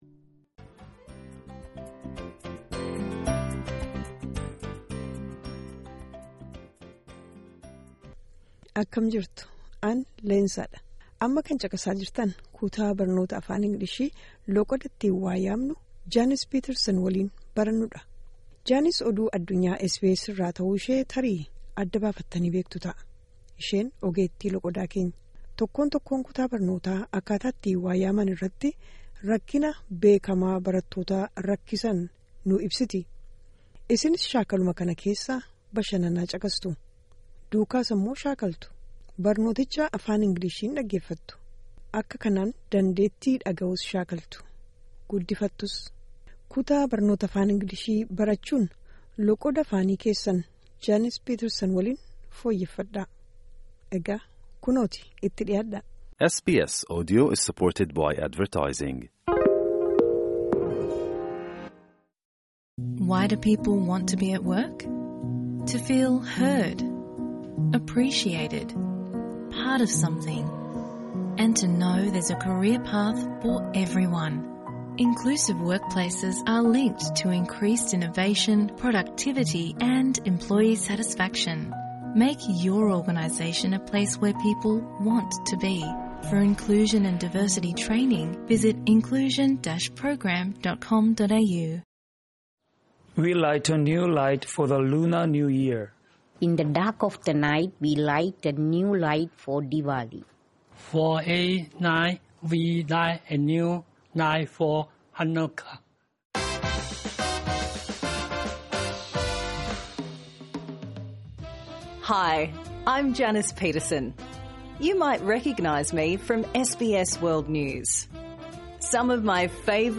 Improve your pronunciation | Season 1